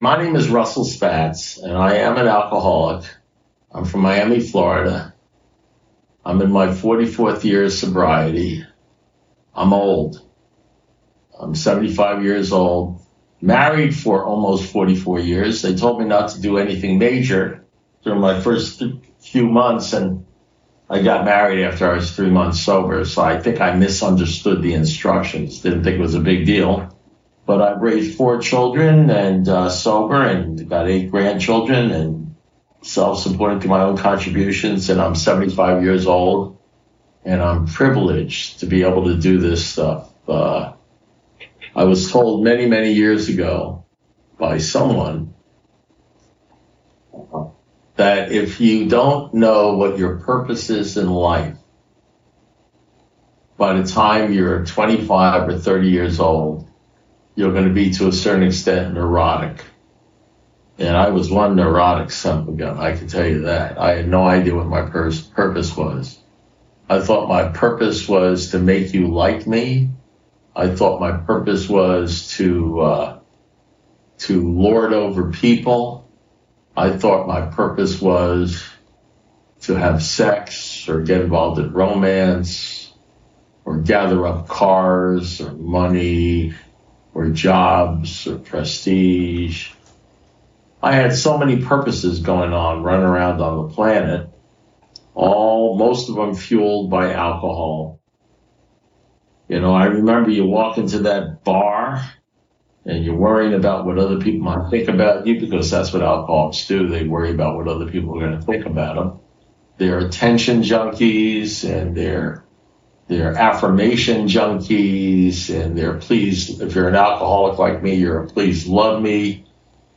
AA Speaker Recordings AA Step Series Recordings Book Study